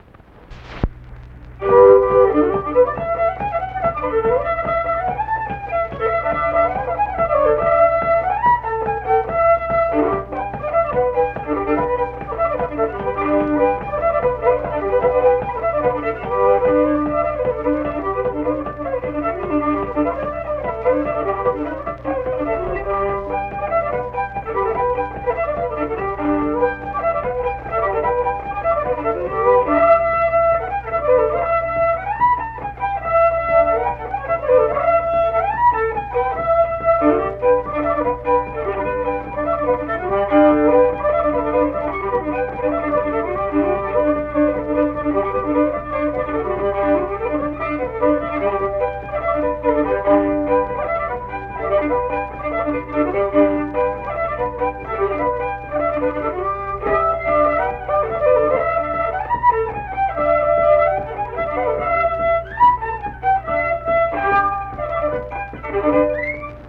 Unaccompanied fiddle music
Instrumental Music
Fiddle
Mingo County (W. Va.), Kirk (W. Va.)